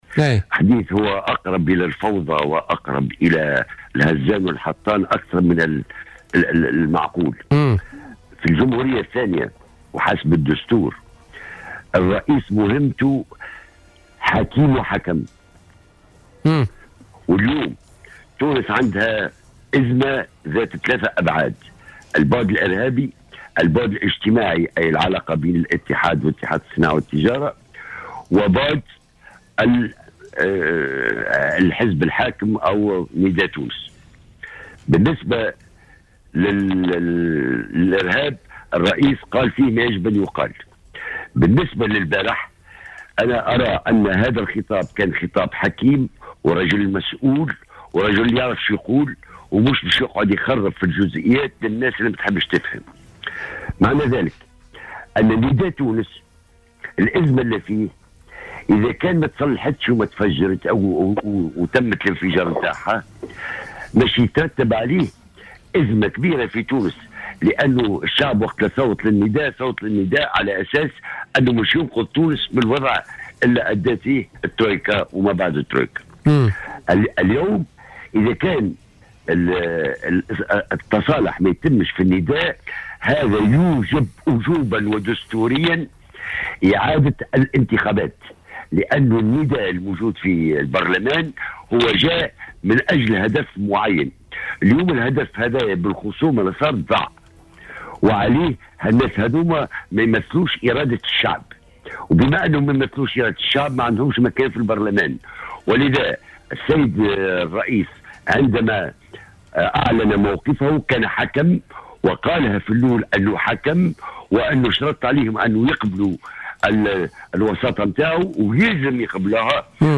وقال في اتصال هاتفي بـ "الجوهرة أف ام" في برنامج "بوليتيكا"، إذا لم تتم المصالحة فيجب حل البرلمان والدعوة لانتخابات مبكرة بقرار من رئيس الجمهورية، بحسب تعبيره.